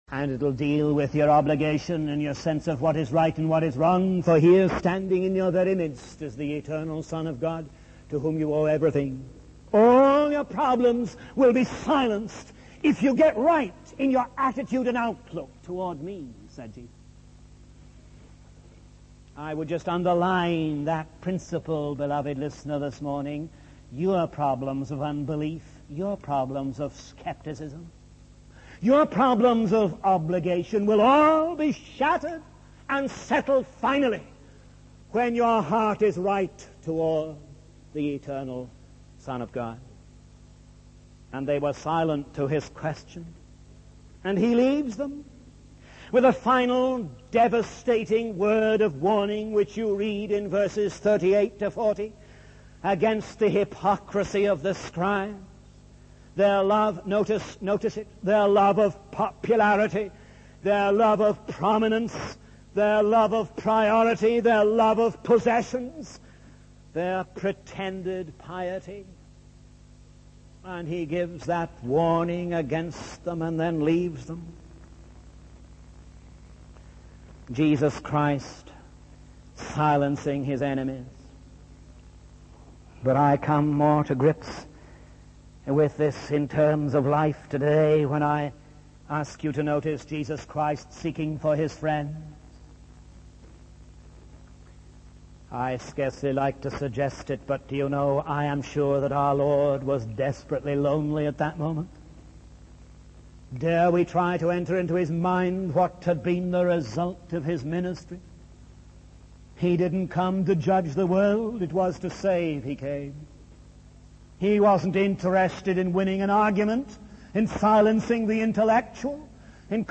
In this sermon, the preacher focuses on Jesus' actions and observations in the temple.